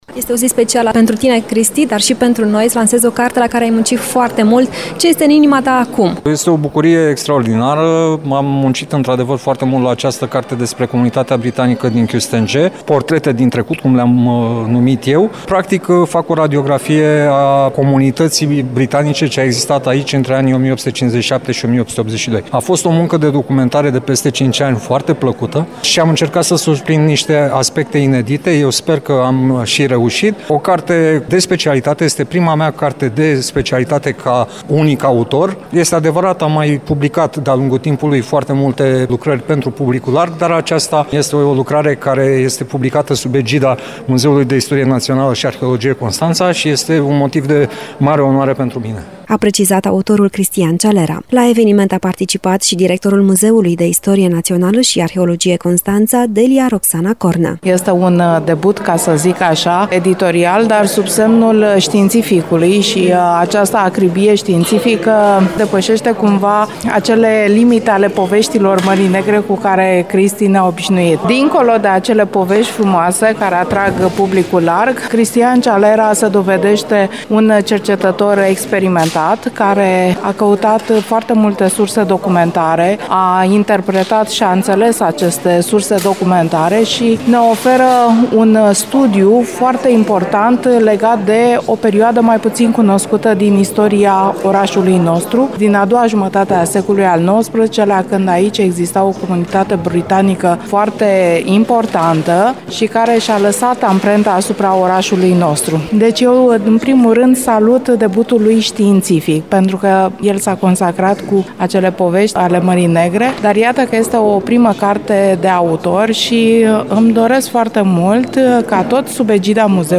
prezent la evenimentul de lansare a cărții.